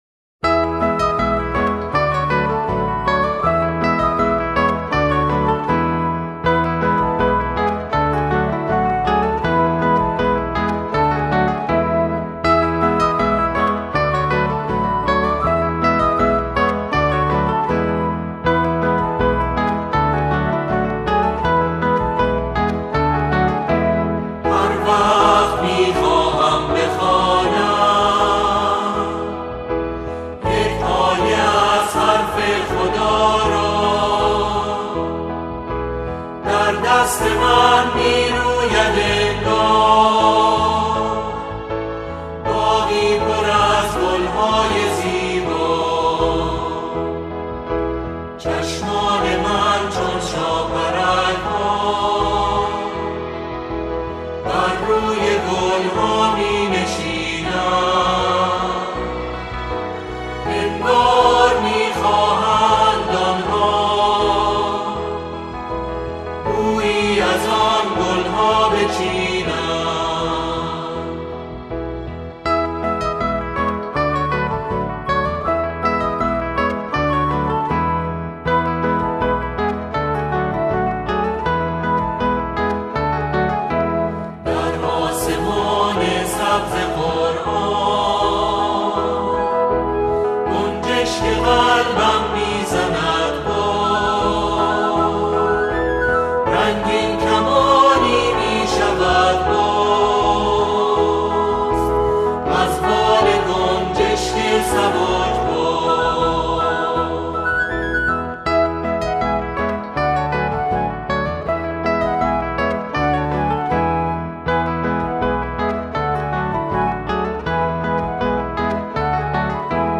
در این اثر، آن‌ها، شعری را درباره قرآن همخوانی می‌کنند.